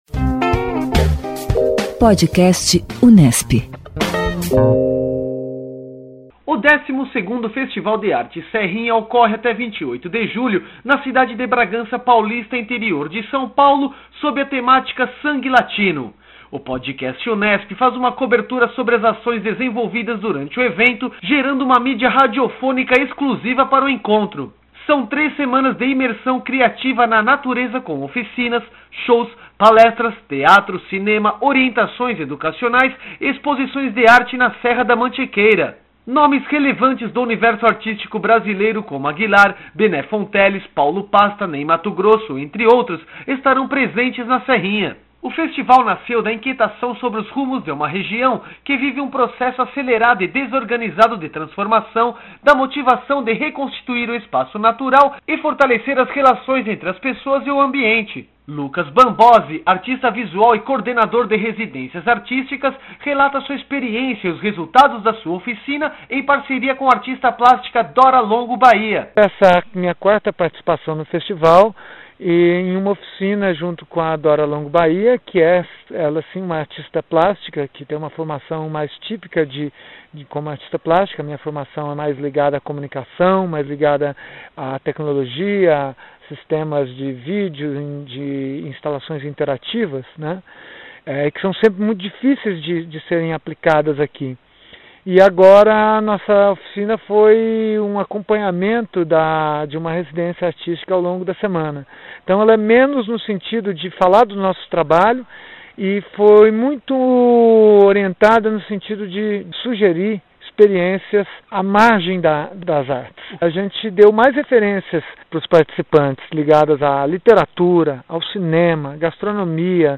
[Festival de Arte Serrinha 2013] Residência artística sugeriu experiências à margem das artes, explica artista visual